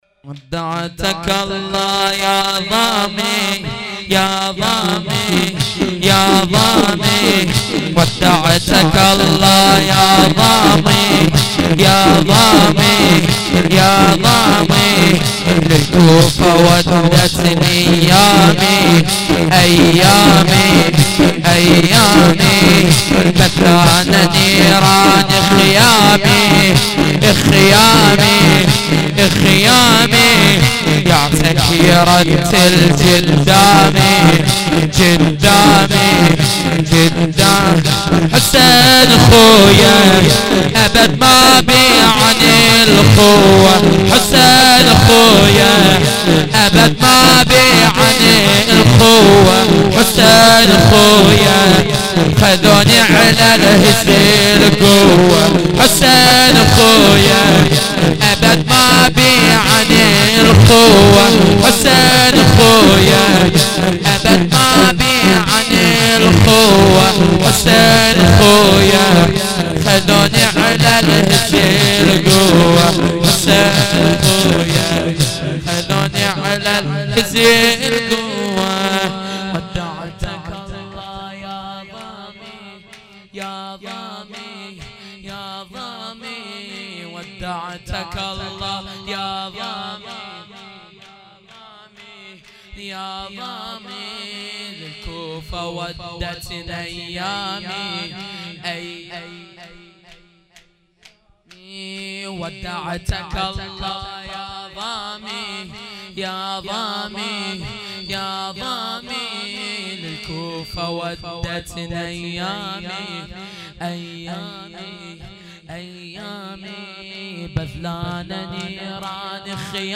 shadat-piambar-v-emam-hasan-92-shor-arabi.mp3